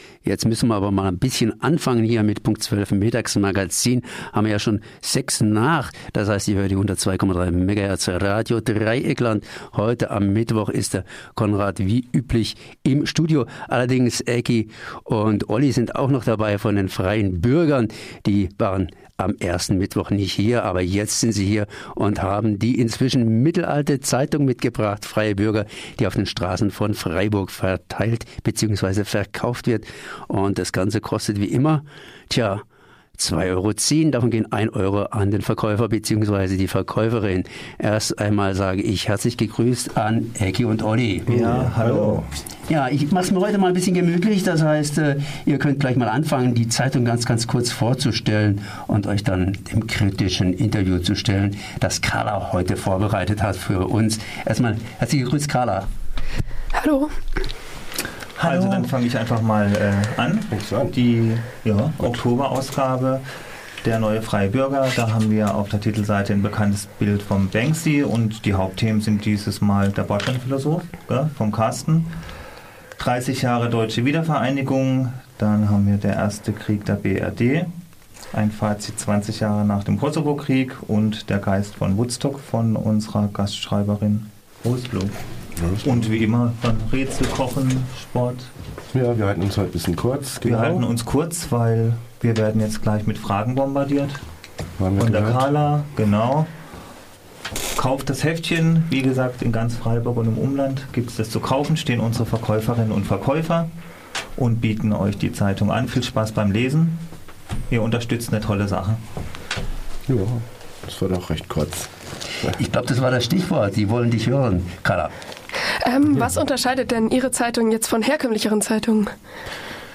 Möglichst viel live und mit Studiogästen.